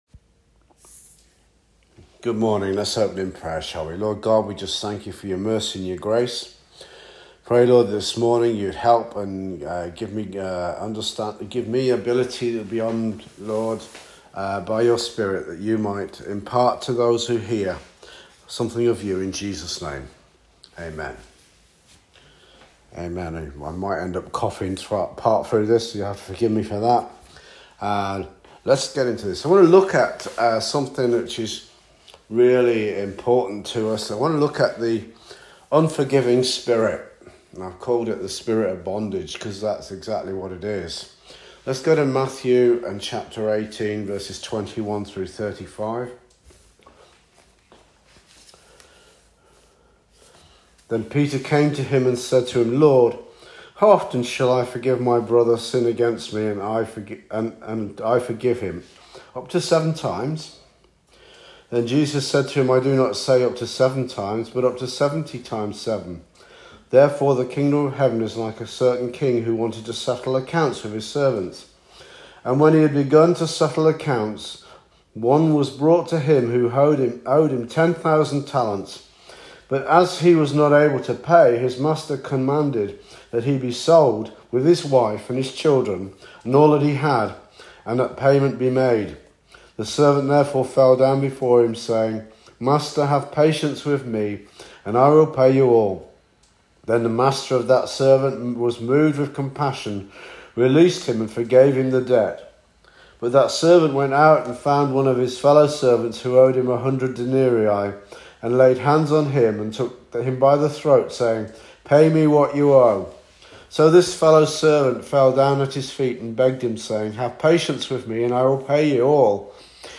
SERMON “THE WORST PRISON”